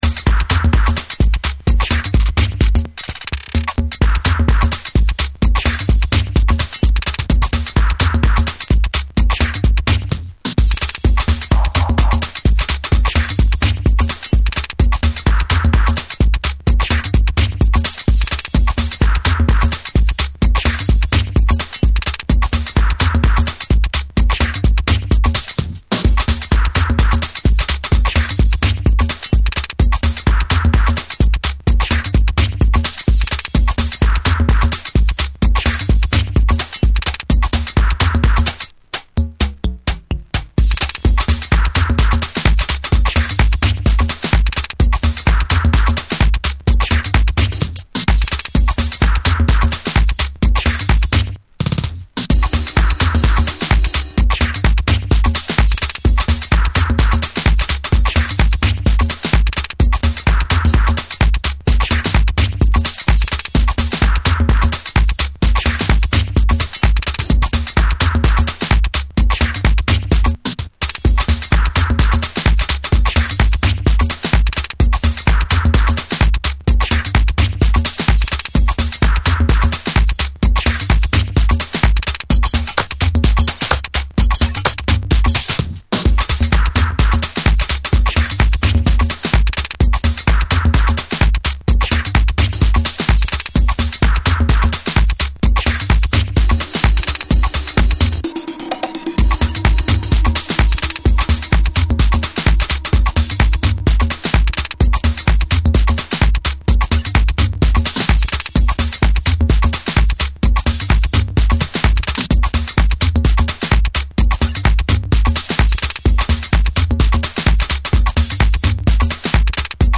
ファンキーなベースラインとトライバルなリズムが絡み合うディープなミニマル・ハウス
軽やかで抜けの良いビートにファンク要素が映えるグルーヴィーな
TECHNO & HOUSE / ORGANIC GROOVE